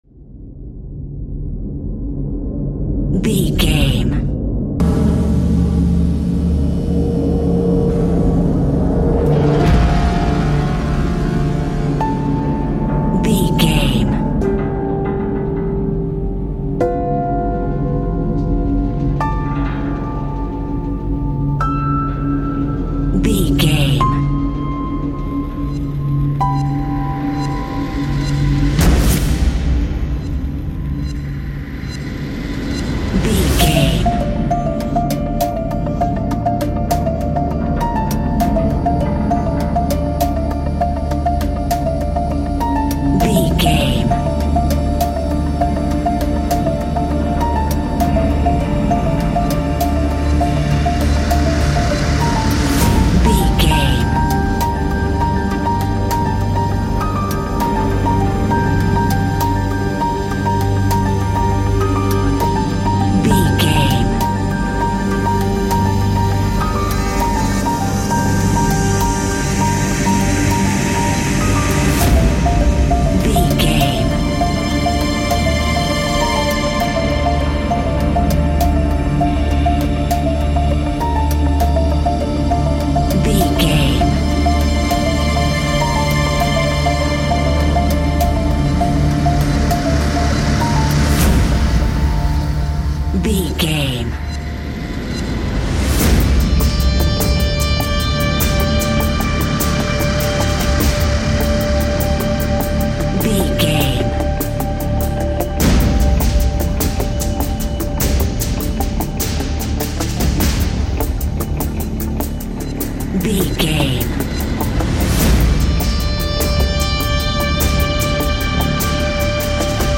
Fast paced
In-crescendo
Ionian/Major
D♭
industrial
dark ambient
EBM
drone
synths
Krautrock